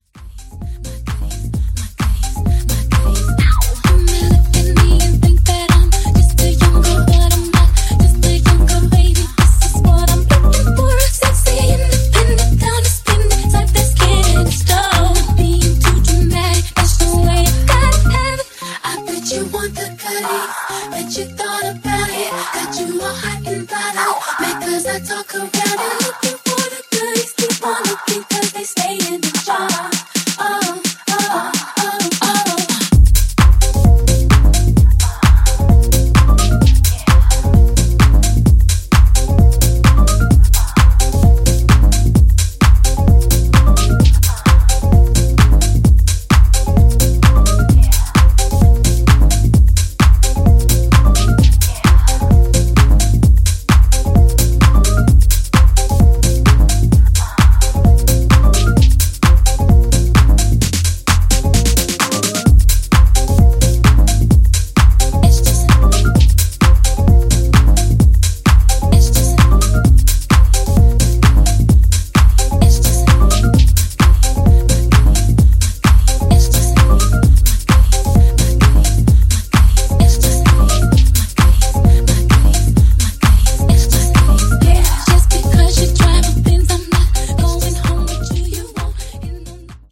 taking a 2000's RnB vocal you thought you knew